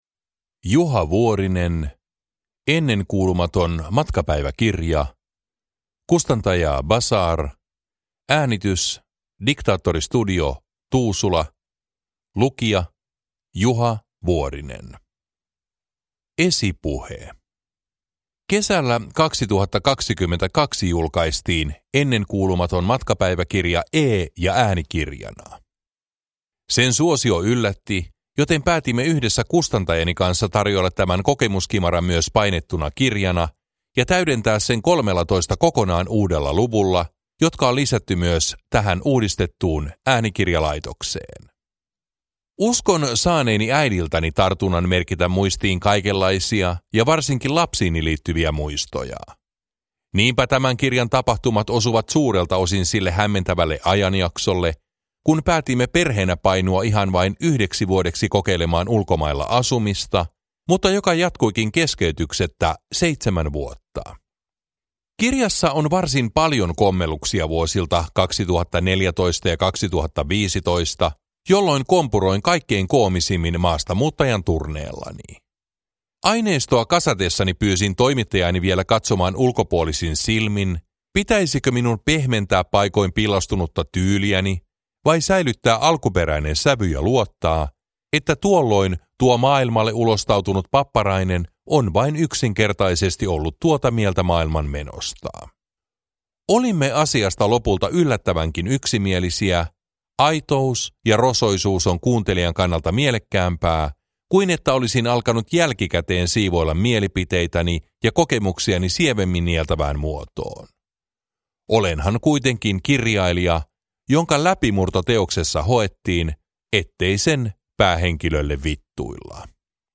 Ennenkuulumaton matkapäiväkirja (päivitetty laitos) – Ljudbok
Uppläsare: Juha Vuorinen